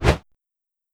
Air Swipe 05.wav